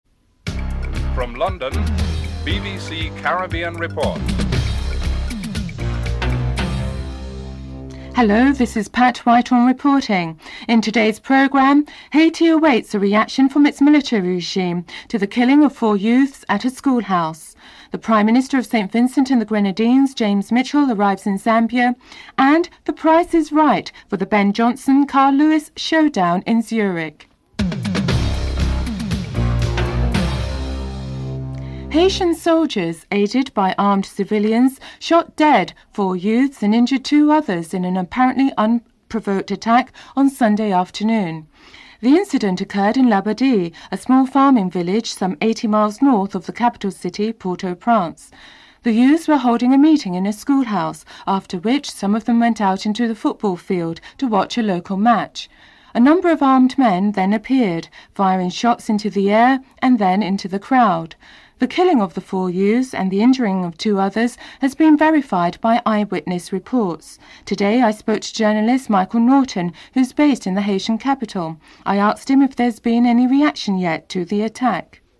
1. Headlines: (00:08-00:27)